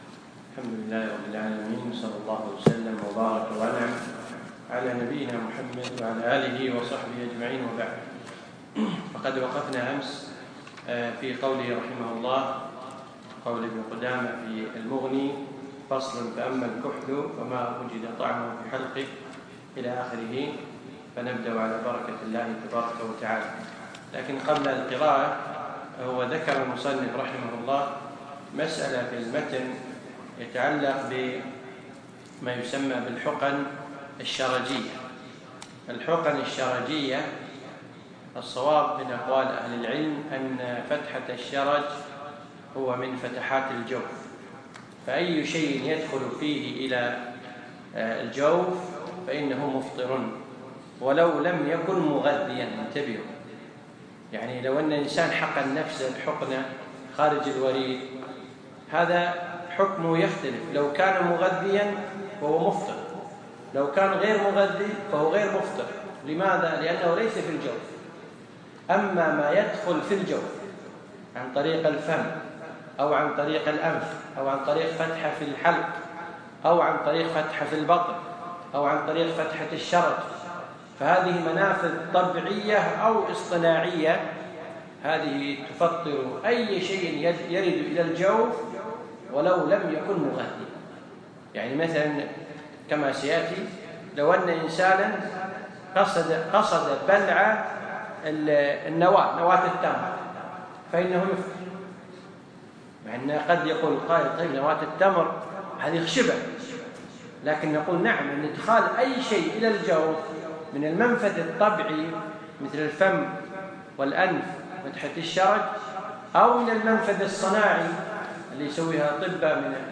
يوم الجمعة 27 شعبان 1437هـ الموافق 3 6 2016م في مسجد أحمد العجيل القصور